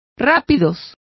Complete with pronunciation of the translation of rapids.